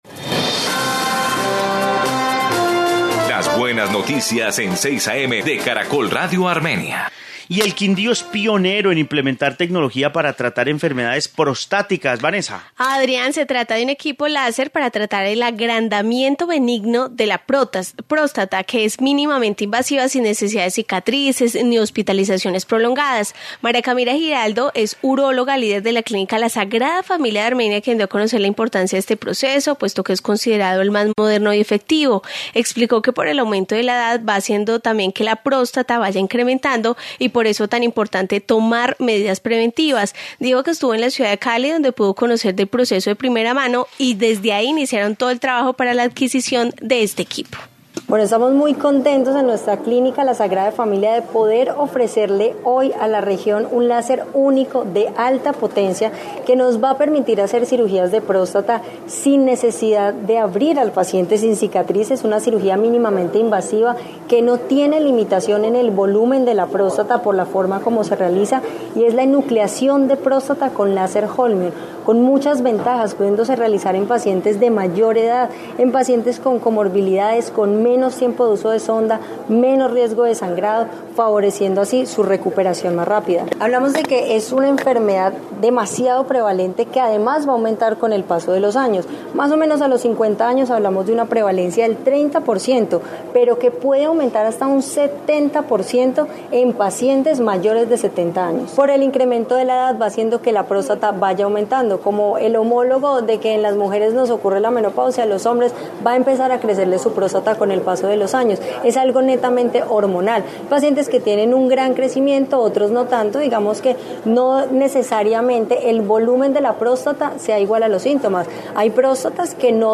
Informe sobre equipo para enfermedades prostáticas